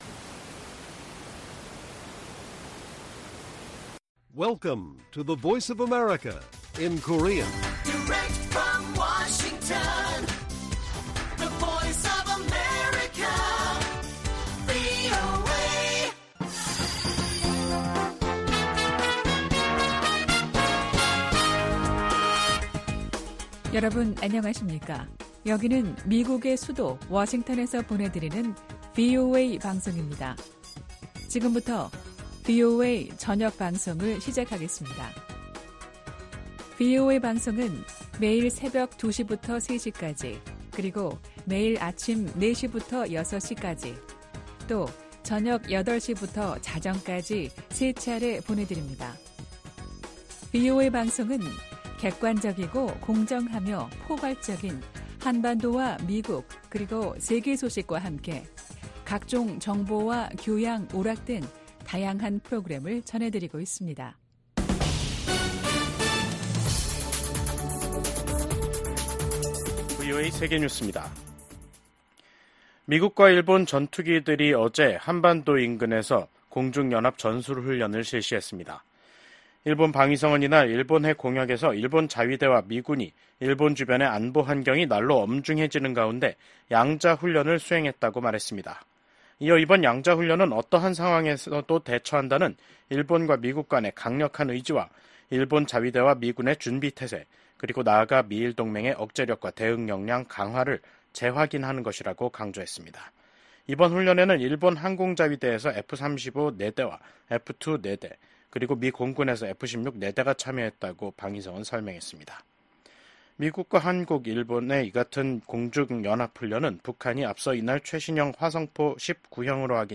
VOA 한국어 간판 뉴스 프로그램 '뉴스 투데이', 2024년 11월 1일 1부 방송입니다. 북한은 신형 대륙간탄도미사일(ICBM) ‘화성-19형’을 시험발사했고 이 ICBM이 ‘최종완결판’이라고 주장했습니다. 러시아에 파병된 북한군이 곧 전투에 투입될 것으로 예상된다고 미국 국무·국방장관이 밝혔습니다. 북한군 8천 명이 우크라이나와 가까운 러시아 쿠르스크 지역에 있다는 정보를 입수했다고 미국 유엔 차석대사가 밝혔습니다.